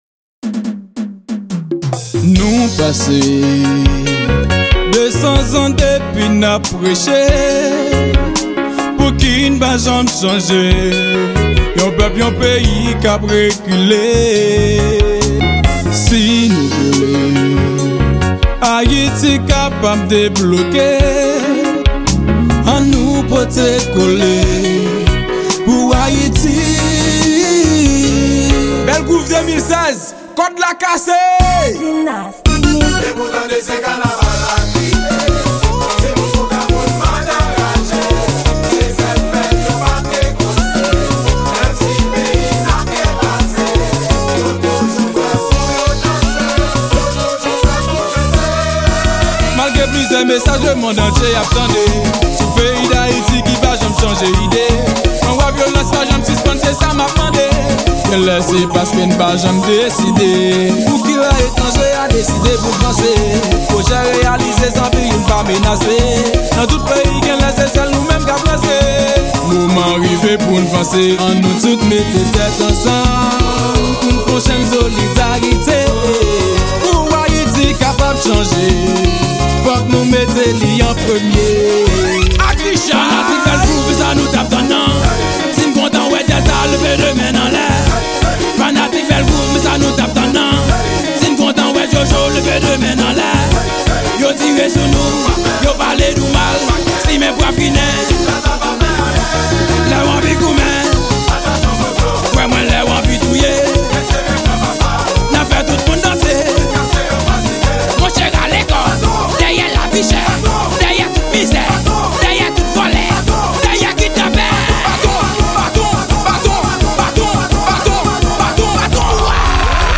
Genre: Kanaval.